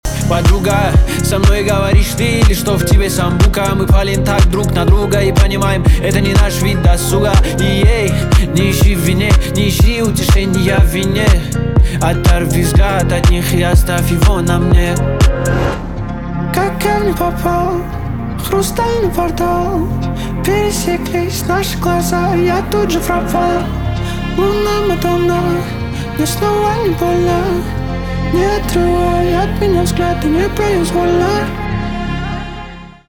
поп
битовые
гитара